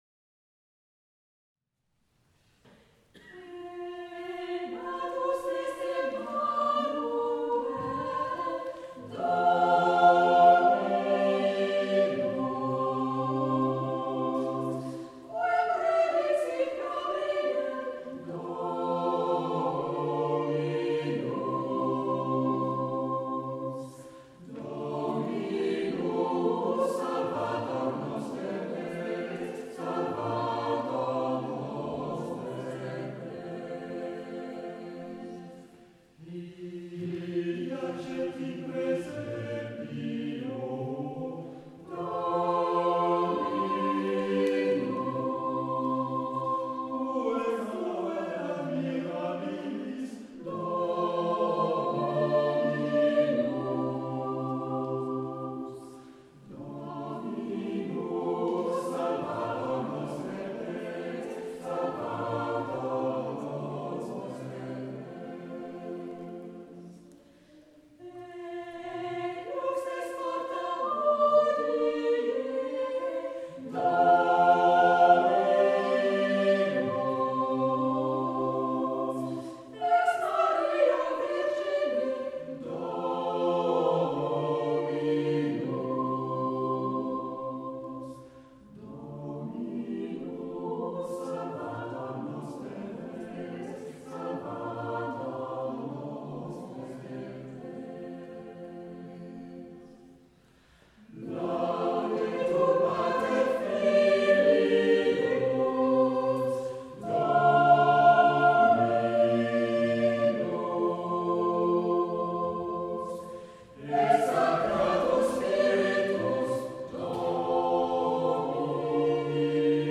Noëls des XIVe au XXIeme siècle